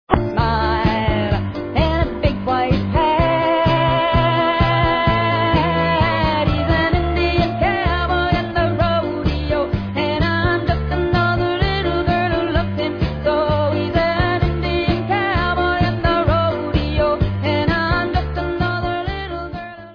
1972 album, recorded in nashville
sledovat novinky v oddělení Folk